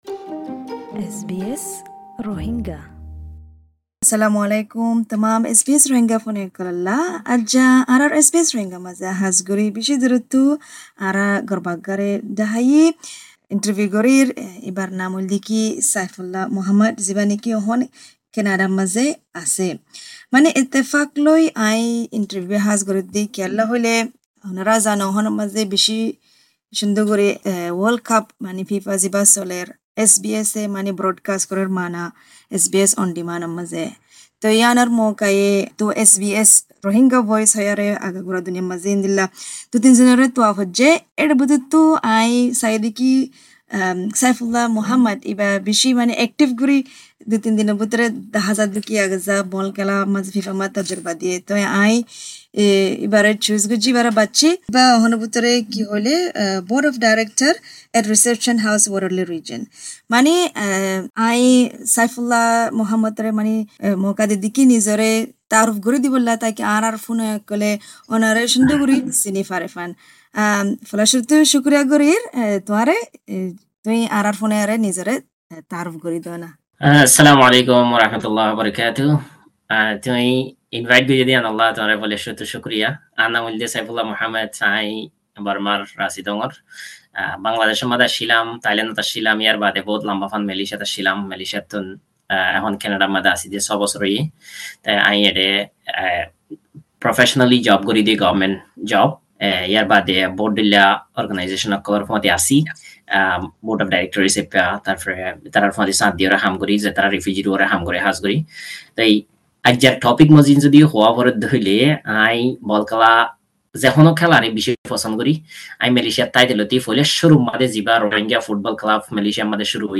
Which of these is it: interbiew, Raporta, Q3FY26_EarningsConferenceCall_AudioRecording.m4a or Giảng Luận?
interbiew